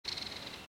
forest_bird6.mp3